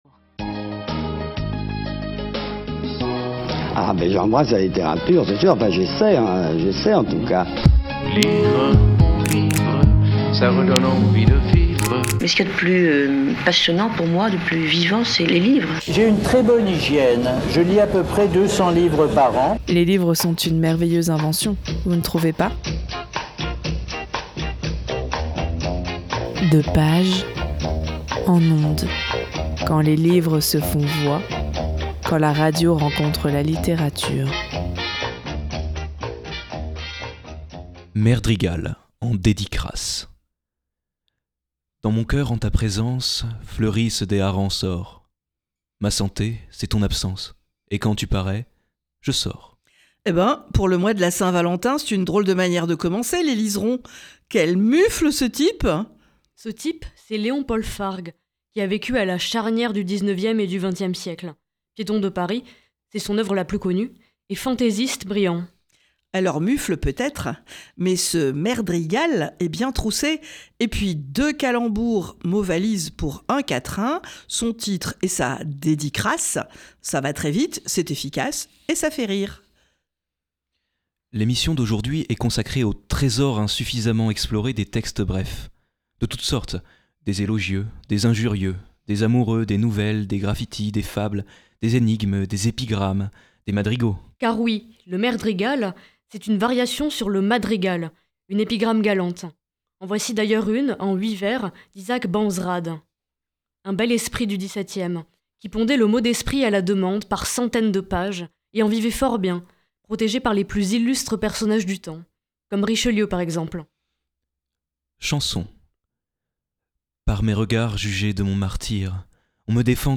Bienvenue dans De pages en ondes, une émission littéraire où la littérature rencontre la radio.
Cette émission est une création originale de l’association de lectures à voix haute en public : Les Liserons lisent.